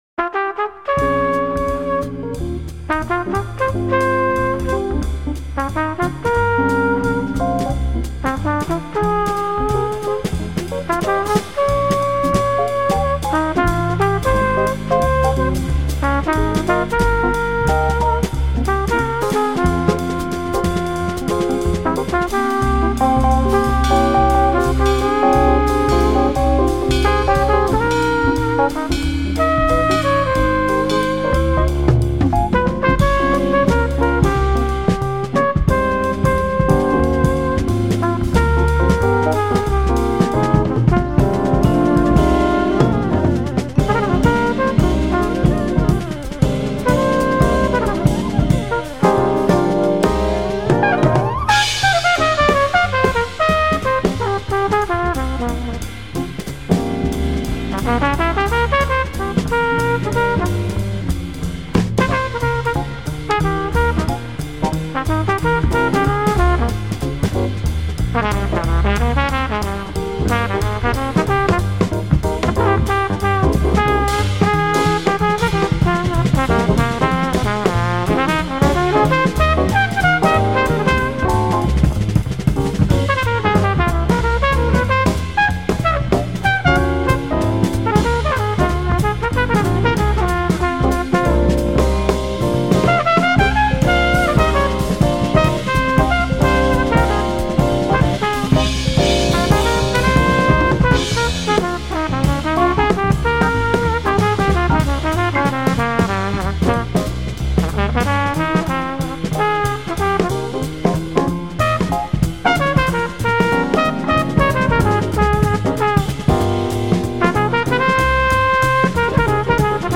Random falling leaves with background music
jazz standard